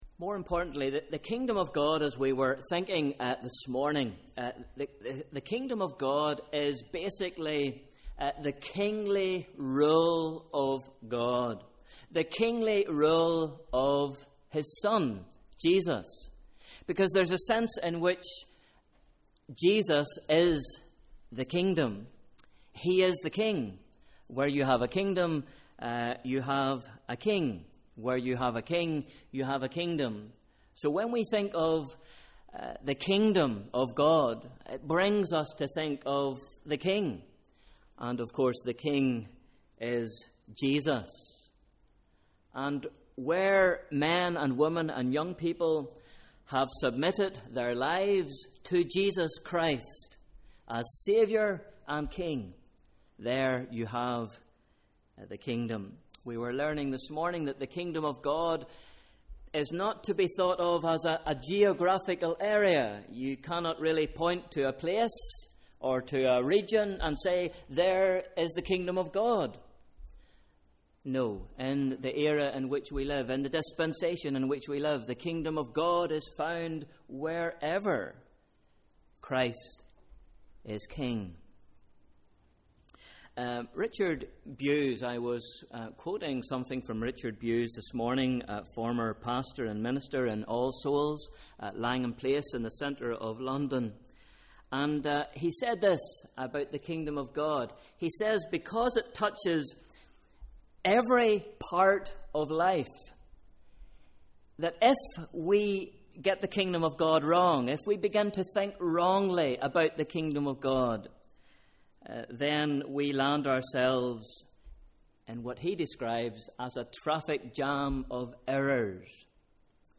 Evening Service: Sunday 7th July 2013